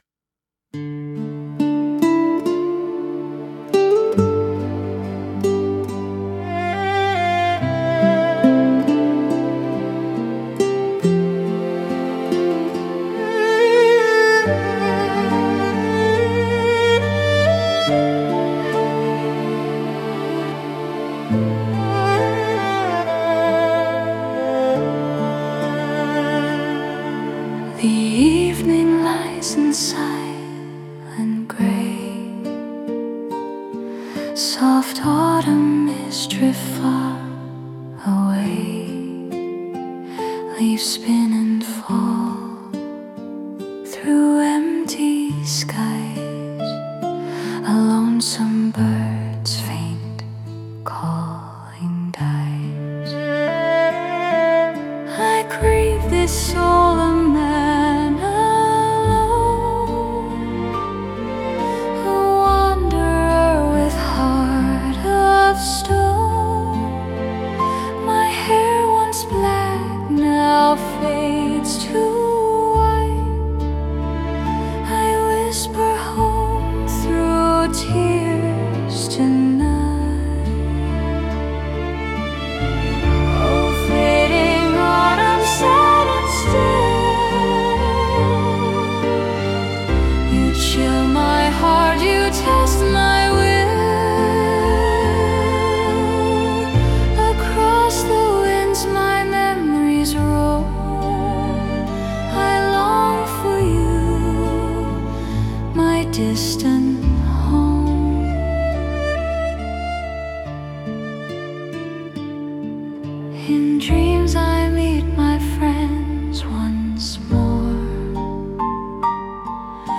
Tempo: ~68–72 BPM (slow 3/4 or 6/8 waltz)
Mood: Nostalgic, melancholic, deeply personal.
Key: A minor or D minor (works beautifully with nylon guitar or piano).
Classical guitar / piano
Violin or erhu for autumn tone
Soft background strings